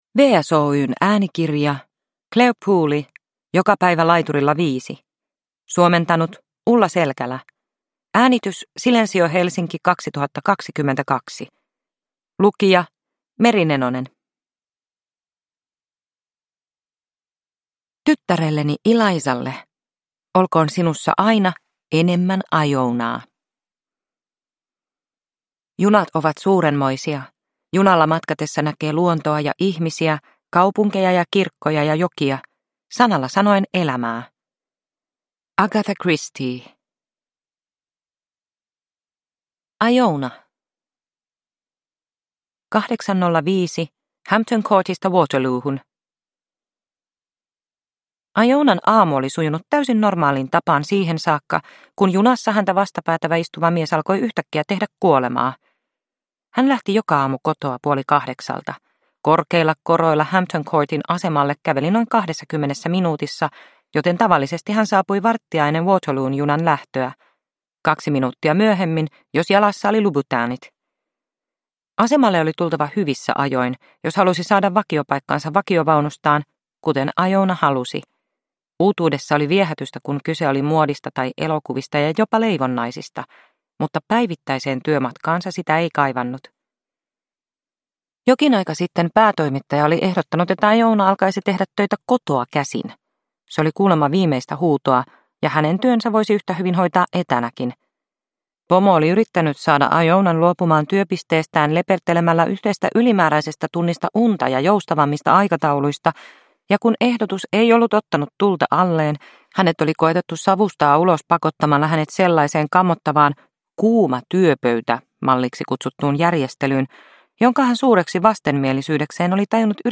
Joka päivä laiturilla viisi – Ljudbok – Laddas ner